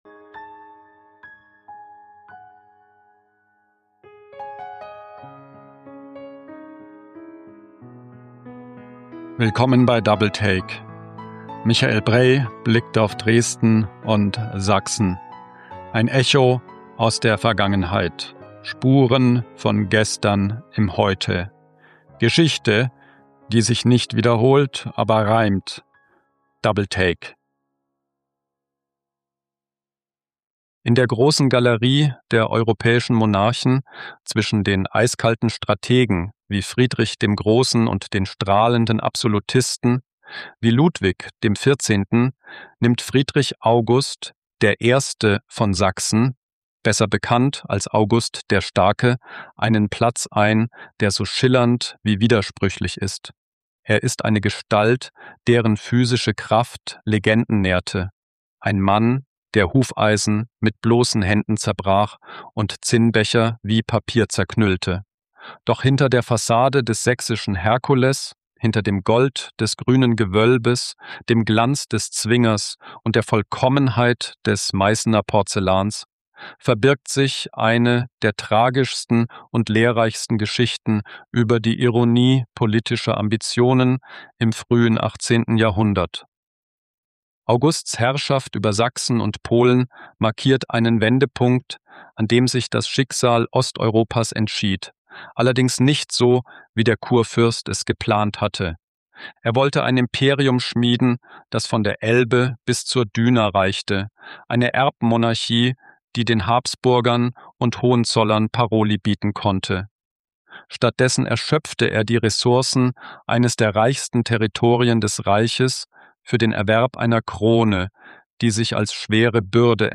In dieser Episode erzählen wir, wie ein barocker Opportunist die Religion zur Ware degradierte, warum ein Trinkgelage in Rawa Ruska das Schicksal Osteuropas besiegelte – und was es bedeutet, ein politischer Verlierer zu sein, der dennoch unsterblich wird. Die Voiceovers des Hauptteils dieser Episode wurden mit einem KI-Sprachmodell produziert